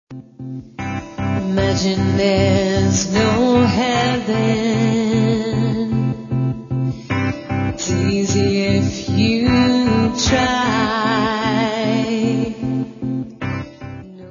zaśpiewała na żywo przebój